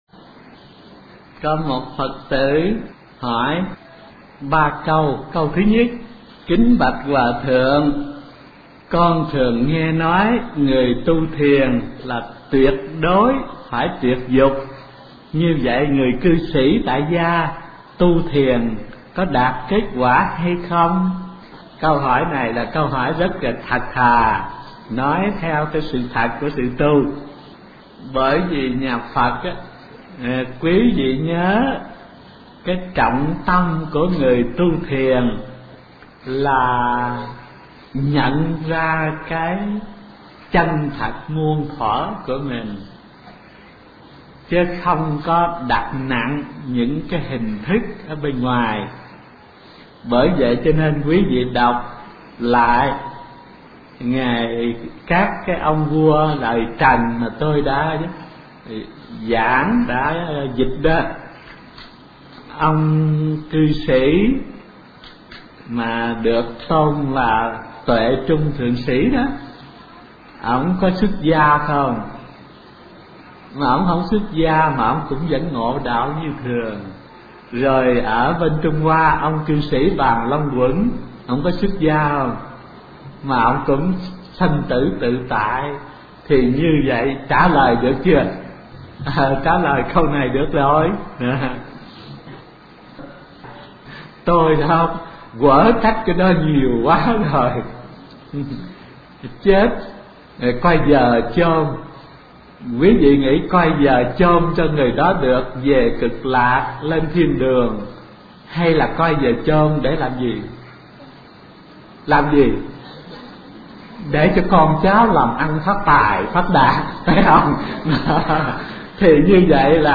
Kinh Giảng Phật Tử Hỏi HT Thanh Từ Đáp 2 - Thích Thanh Từ